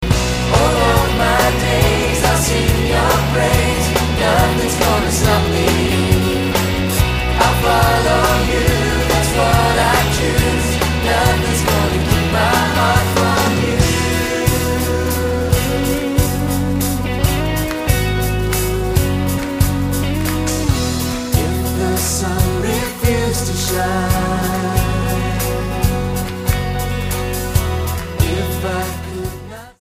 STYLE: Pop
Unlike a lot of contemporary live worship albums